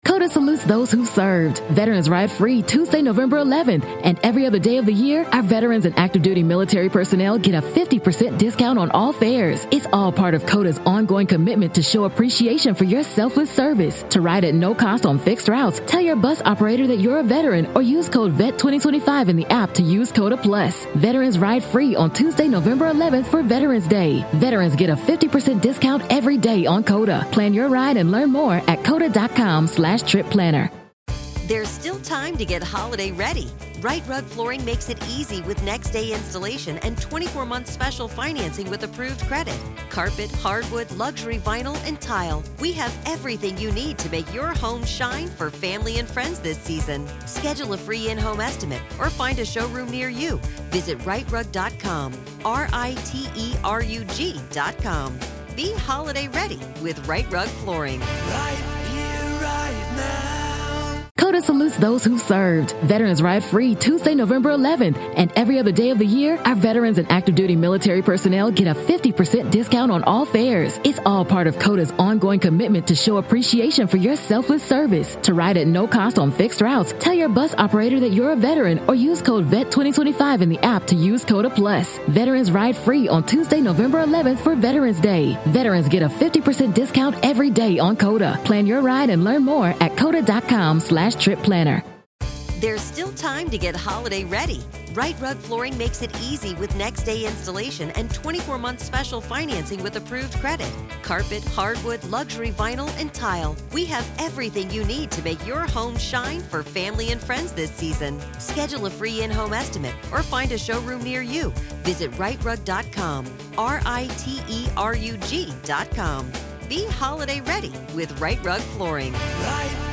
The conversation touches on the complexities of such personality disorders and the necessity of accountability and truth in any potential rehabilitation.